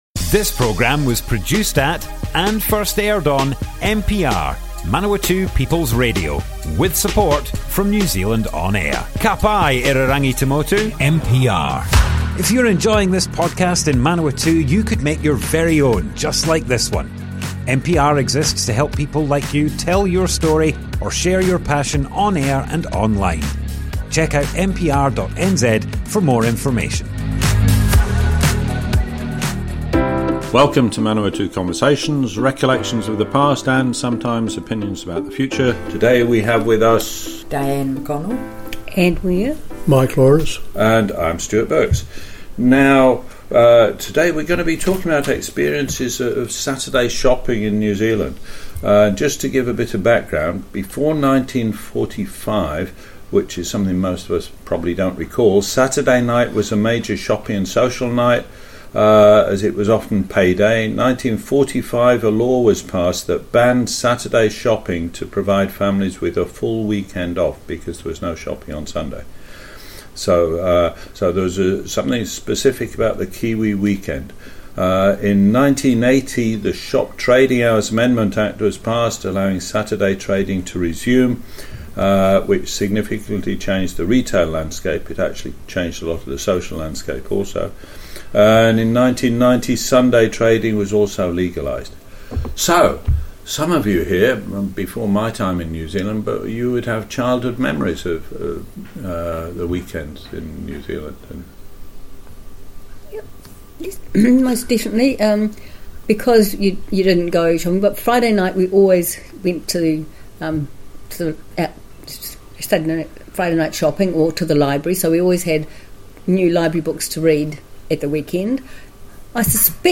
Group discussion, Friday night shopping, Kiwi weekend - Manawatu Conversations - Manawatū Heritage
00:00 of 00:00 Add to a set Other Sets Description Comments Group discussion, Friday night shopping, Kiwi weekend - Manawatu Conversations More Info → Description Broadcast on Manawatu People's Radio, 2nd December 2025.
oral history